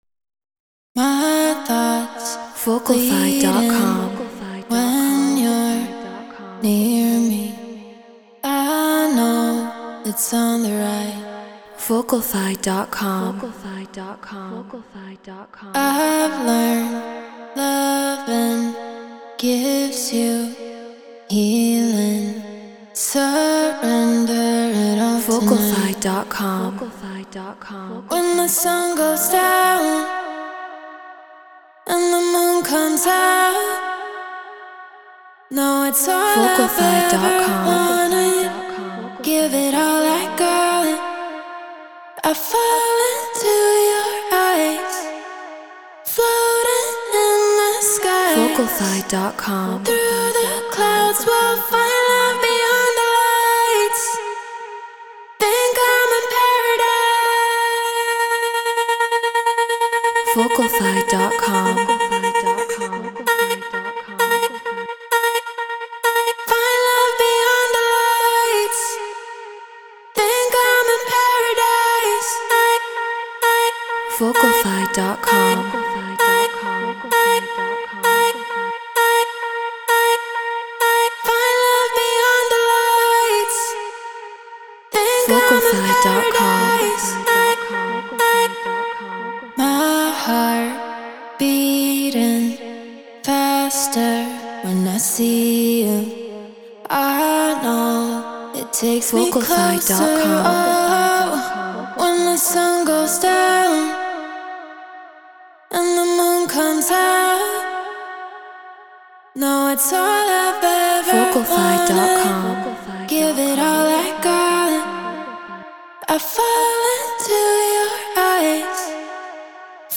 House 130 BPM D#min
Shure KSM 44
Treated Room